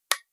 switch8.wav